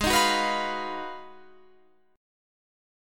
Abm13 Chord
Listen to Abm13 strummed